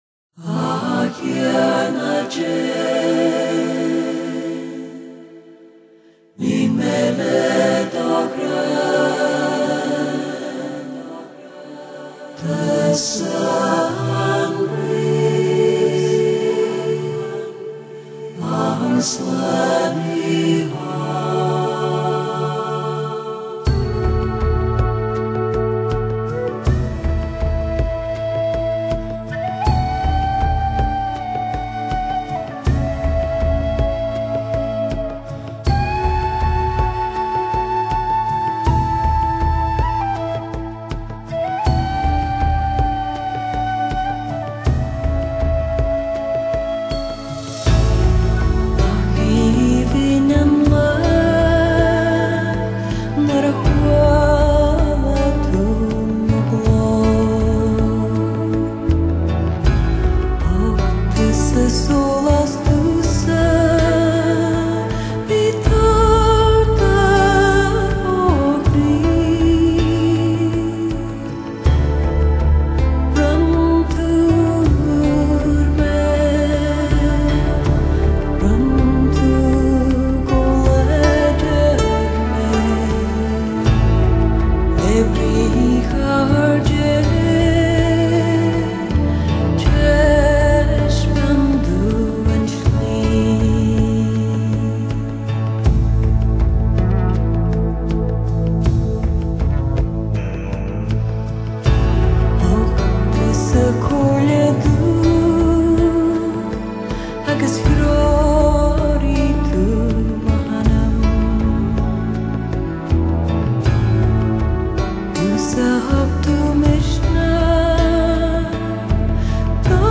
音樂融合了世界音樂、愛爾蘭民族音樂、新世紀音樂、聖樂、流行抒情曲風與電影音樂情境手法。
她耳語般的音調魅力十足，她音樂的美觸摸著你的靈魂。